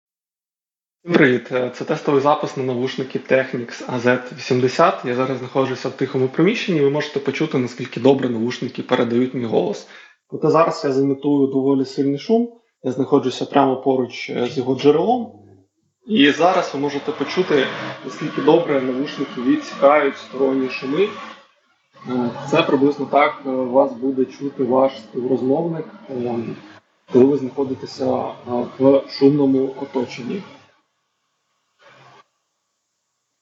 Technics-AZ80-Voice-Sample.mp3